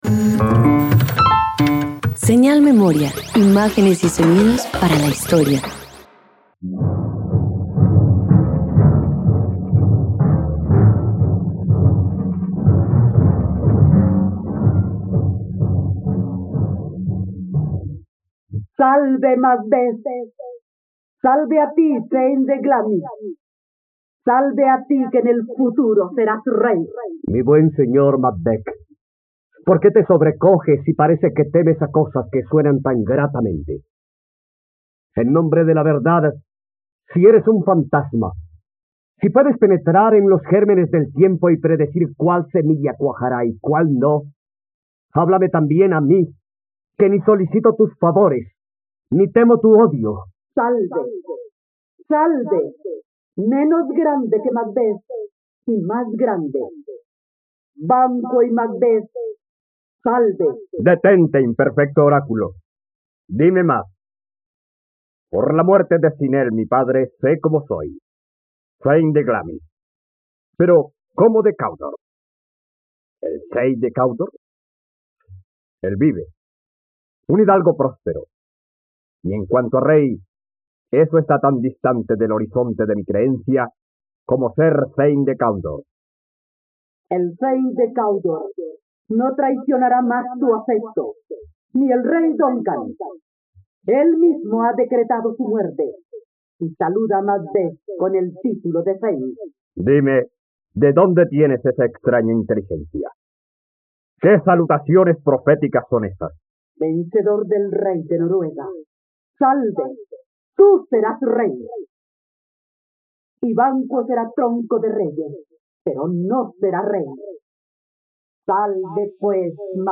..Radioteatro. Escucha la adaptación radiofónica de 'La tragedia de Macbeth' de William Shakespeare.